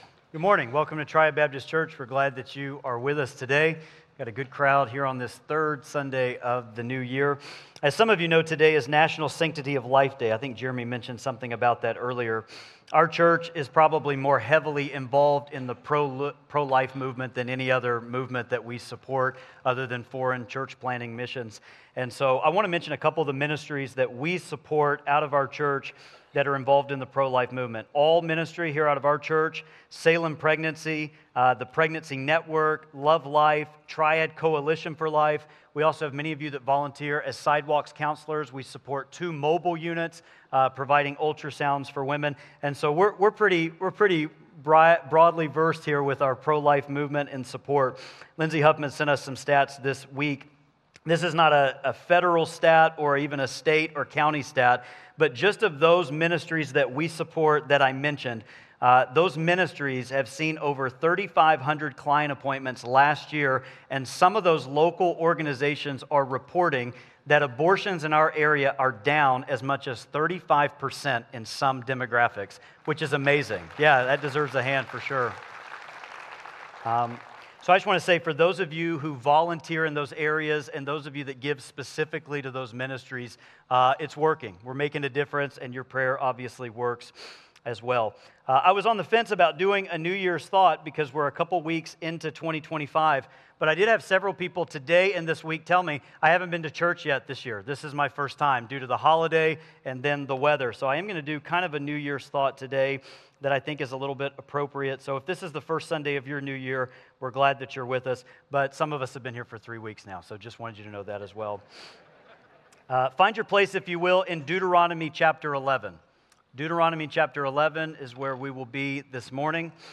Sermons
Sermon Archive